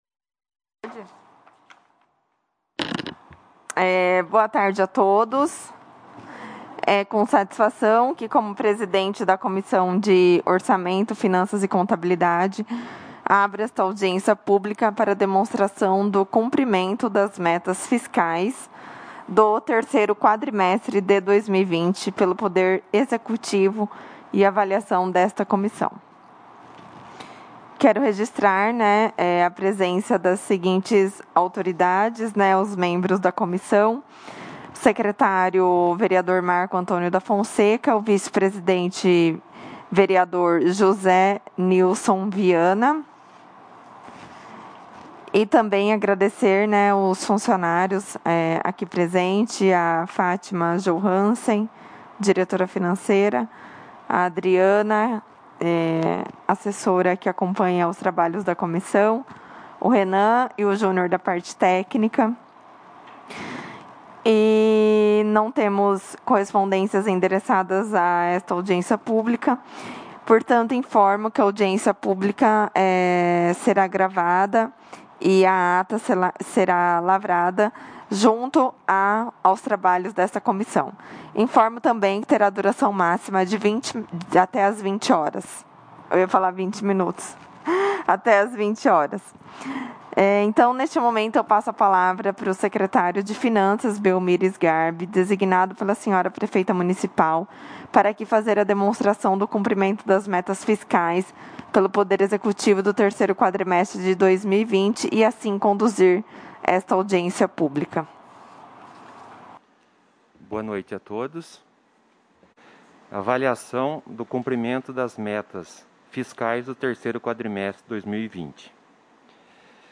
Audiência Pública de 24/02/2021